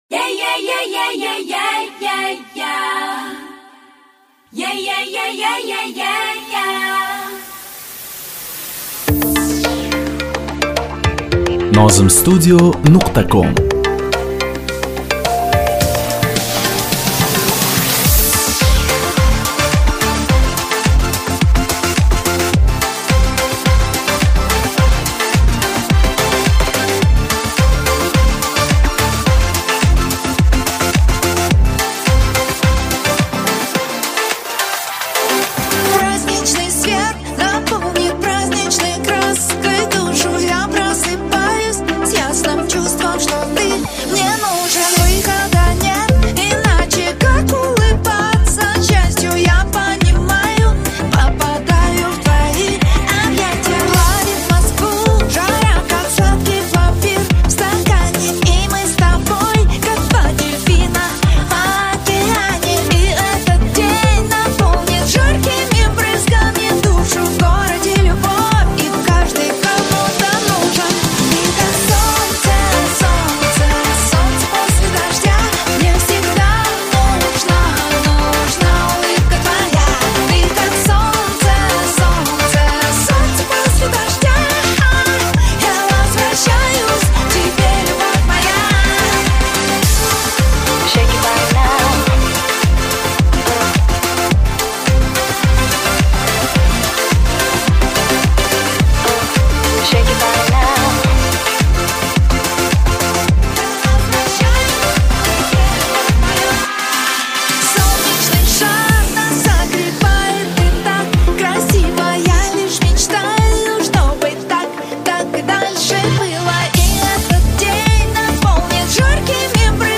minus